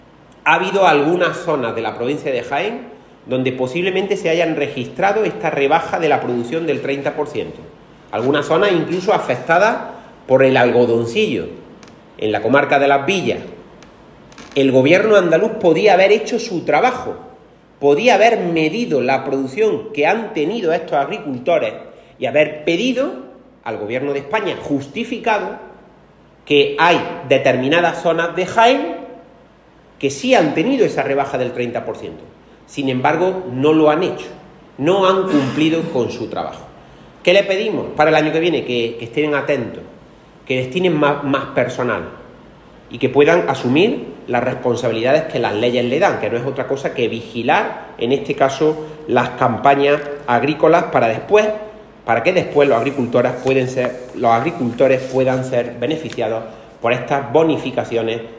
En rueda de prensa, Latorre puso ejemplos concretos que demuestran su afirmación y que contradicen los bulos propagados por el PP y alguno de sus alcaldes.
Cortes de sonido